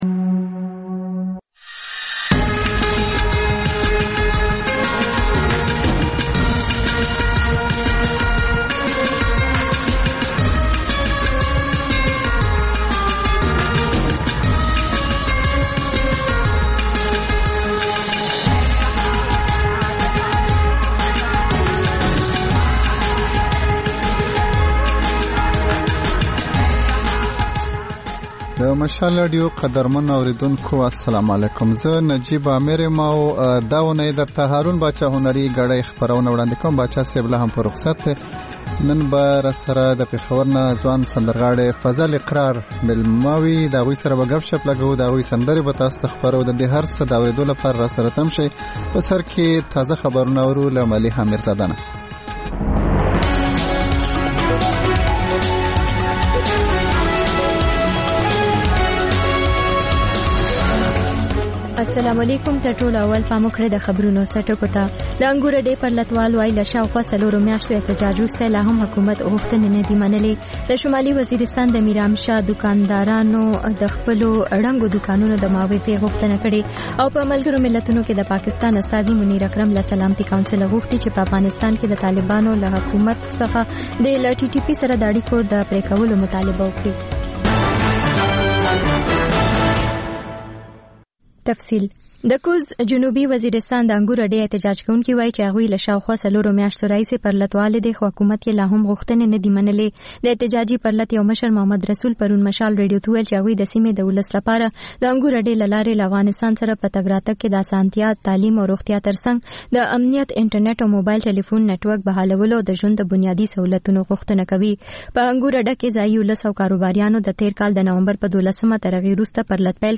د مشال راډیو ماښامنۍ خپرونه. د خپرونې پیل له خبرونو کېږي، بیا ورپسې رپورټونه خپرېږي.